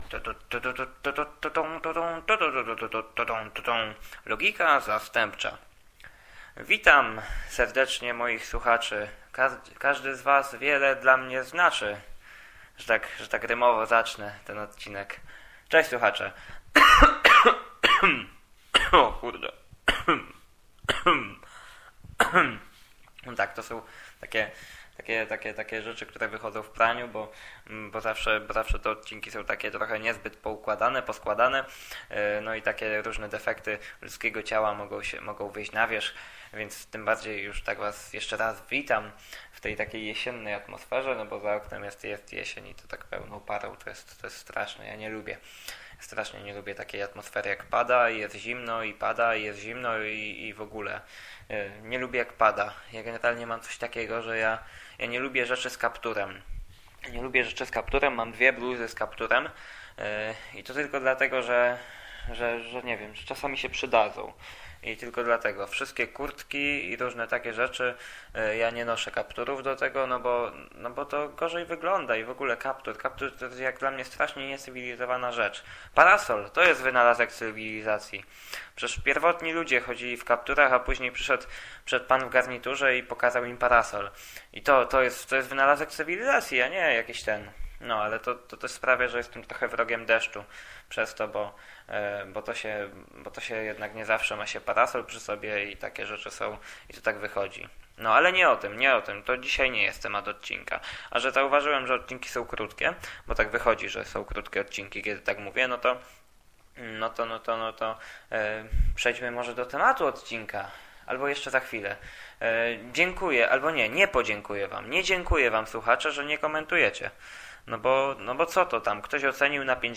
W dzisiejszym odcinku opowiadam o polskiej propagandzie, szczególnie o tej w autobusach. Jest też jedna piosenka, którą improwizowałem, jak to mam w zwyczaju.